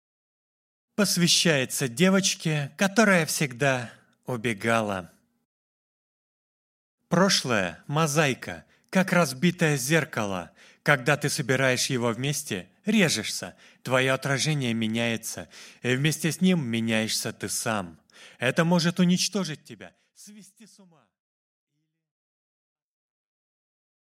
Аудиокнига Девочка и стол | Библиотека аудиокниг